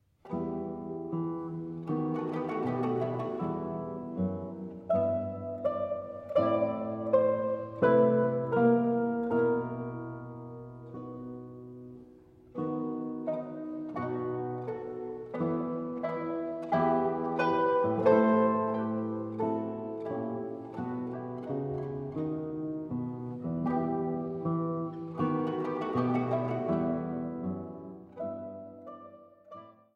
Laute & Mandoline